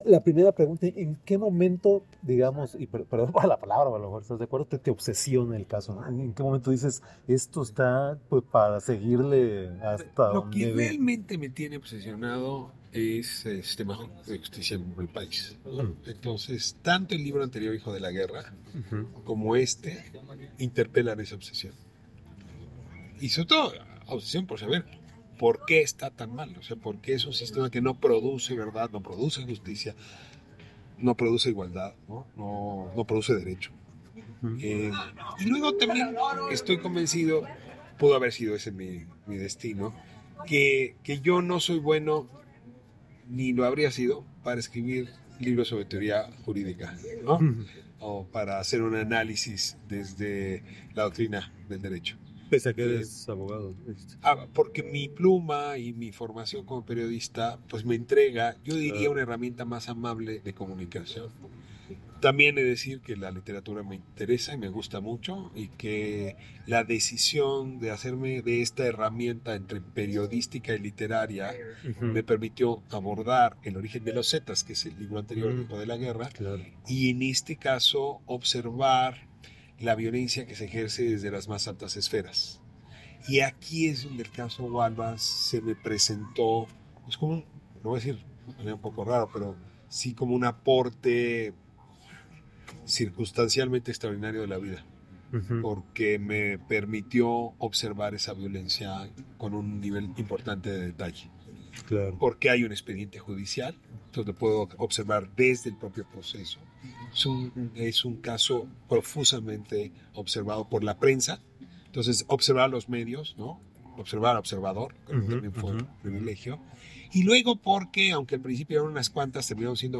El periodista Ricardo Raphael habla en entrevista sobre su libro "Fabricación", donde exhibe las inconsistencias del caso Wallace.